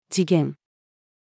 時限-female.mp3